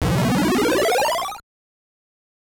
HammerHit.wav